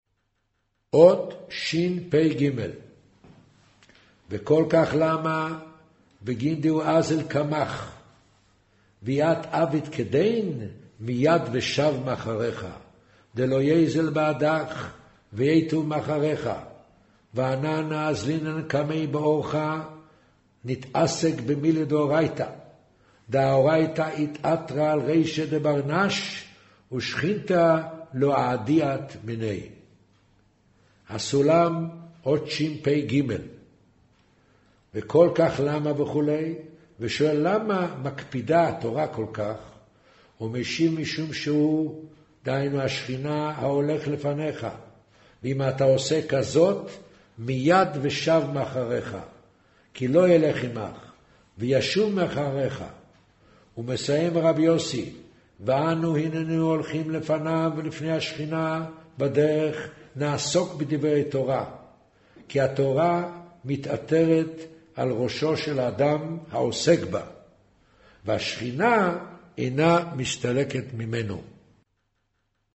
קריינות זהר, פרשת נח, מאמר ויאמר ה' הן עם אחד